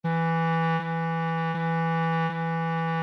Piano (Corda percutida) | Orquestra de cARTón (ODE5)